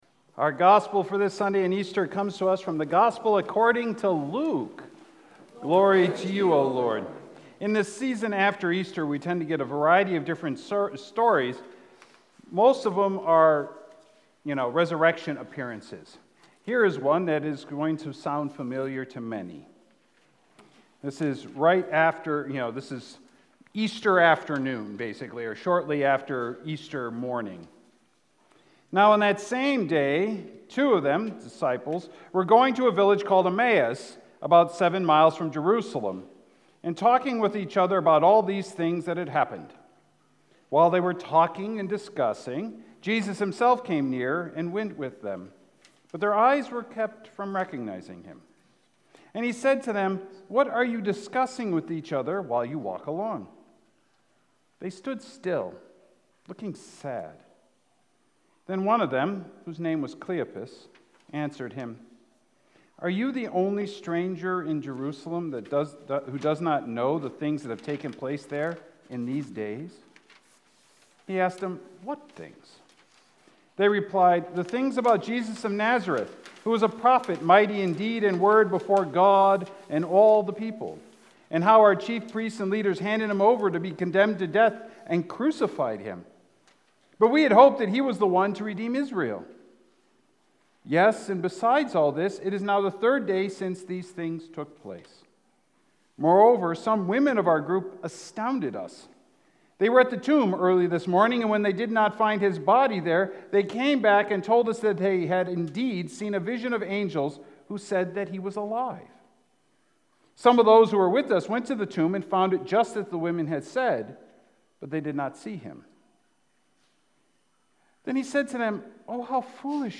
Sermons | Beautiful Savior Lutheran Church
Sunday, April 23, 2023 Third Sunday of Easter